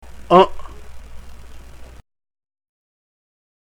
THE PRONUNCIATION PRACTICE
• The nasalized vowels, especially the central nasalized vowels (e.g.,